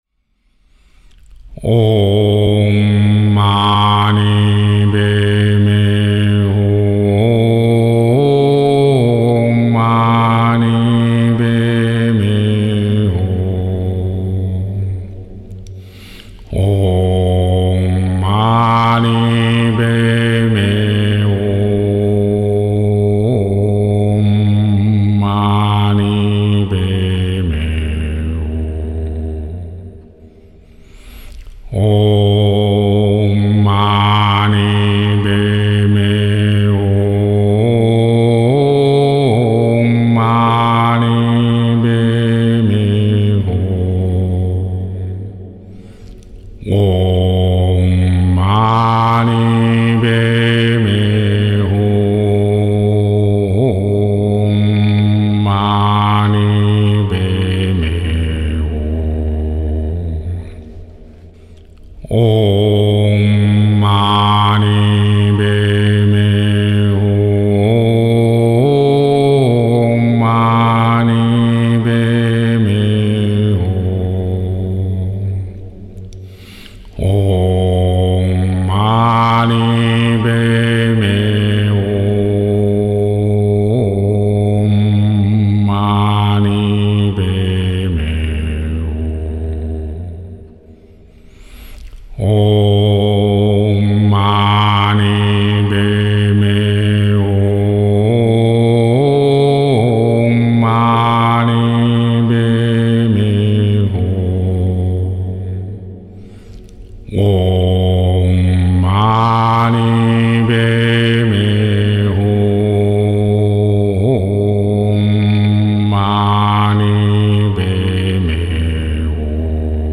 六字大明咒清淨唱誦 《獨唱》Six Character Great Bright Mantra Solo (32’21”) 試聽南無阿彌陀佛清淨唱誦 《獨唱》Namo Amitabha Chant Solo (31’26”)